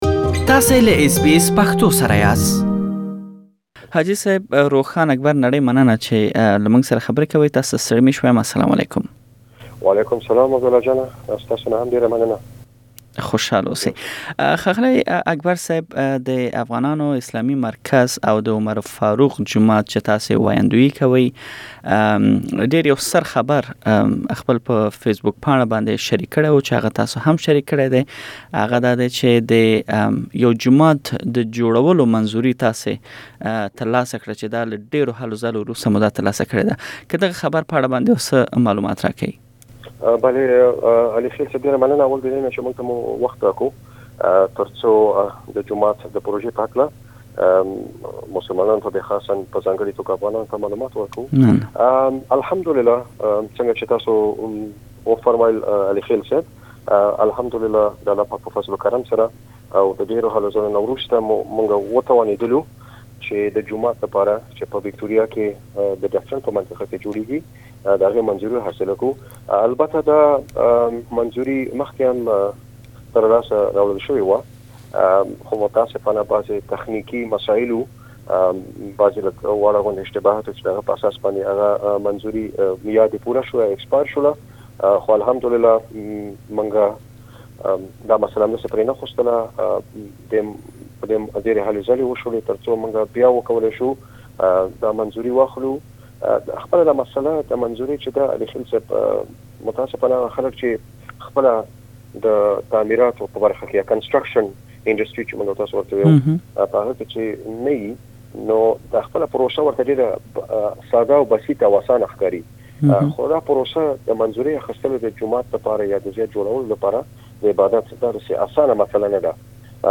تاسې لا نور ډير معلومات په مرکه کې واورئ.